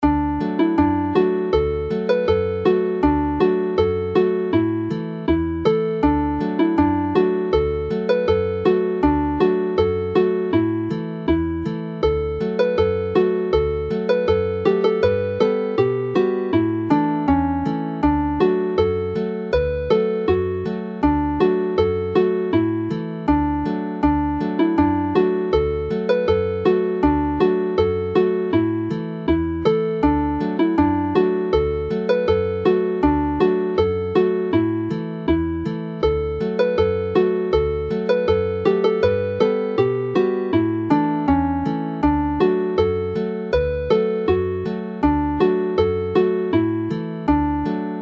Hela'r Geinach (Hunting the Hare) is clearly another pipe tune which is more lively whilst Aden y Frân Ddu (the Black Crow's Wing) is livelier still.
Play the melody slowly